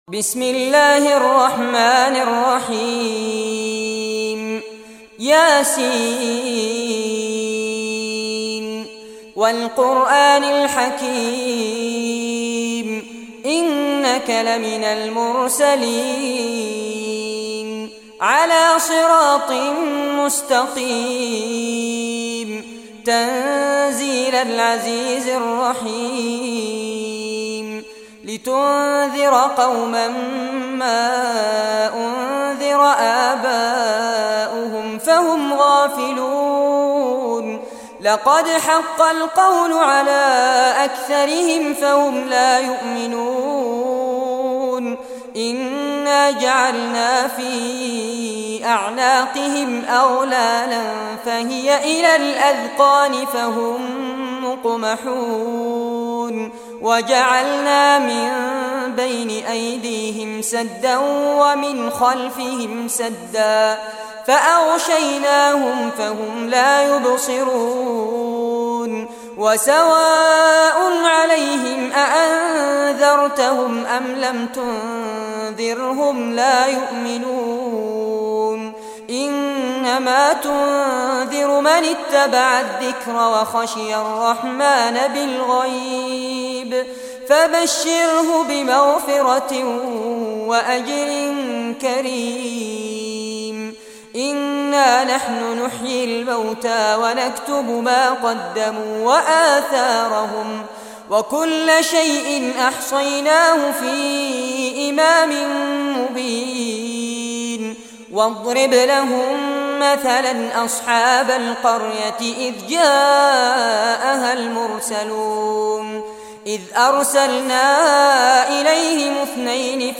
Surah Yasin Recitation by Fares Abbad
Surah Yasin, listen or play online mp3 tilawat / recitation in arabic in the beautiful voice of Sheikh Fares Abbad.
36-surah-yaseen.mp3